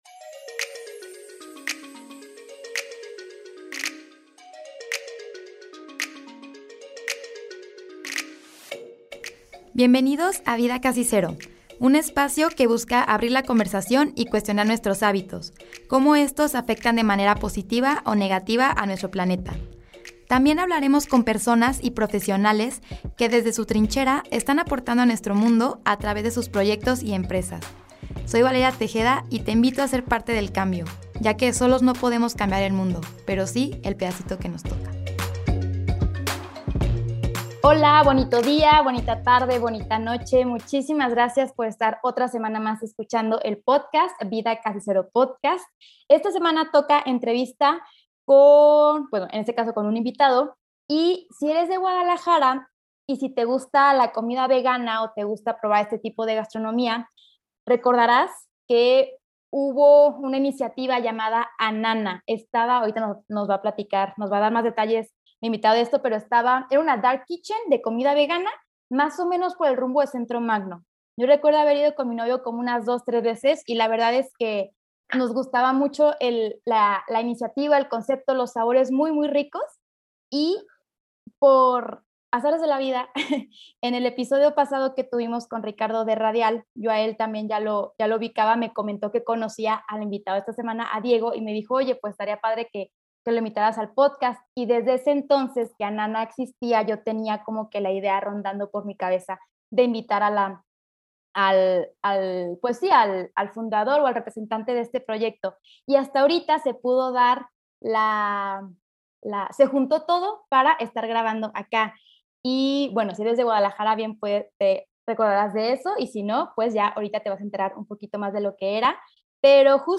Además, estaba emocionada por entrevistarlo ya que fue co fundador de un proyecto vegano en GDL único a mi parecer Hablamos de la diferencia entre veganismo y basado en plantas, cómo ha sido su transición dentro del mundo gastronómico, cómo era un día en la cocina con Anana y más detalles.